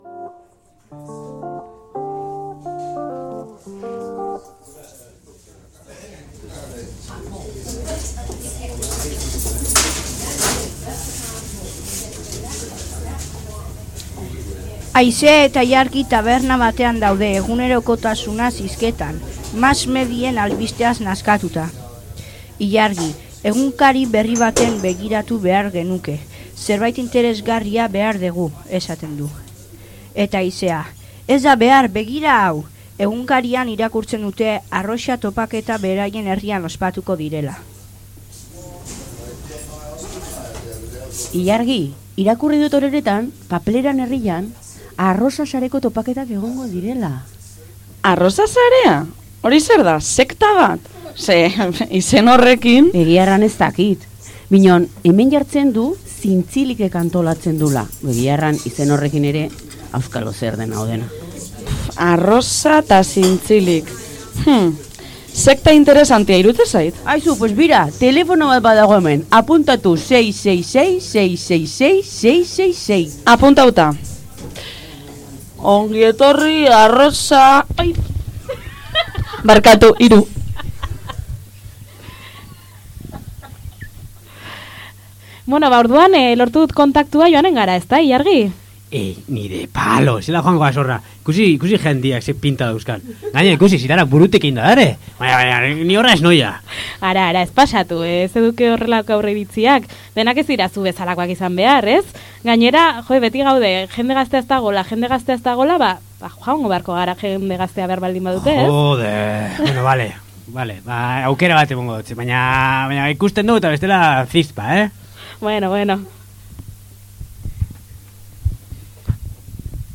Arrosaren 2023.urteko irrati nobela